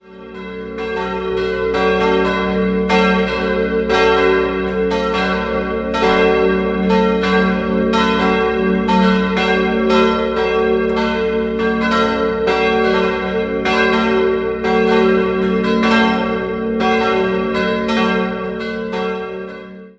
3-stimmiges TeDeum-Geläute: fis'-a'-h' Die beiden größeren Glocken wurden 1952 vom Bochumer Verein für Gussstahlfabrikation in V7e-Rippe gegossen, die kleine Bronzeglocke stammt noch aus der Anfangszeit der Kirche und ist ein Werk von Eduard Becker (Ingolstadt) aus dem Jahr 1881.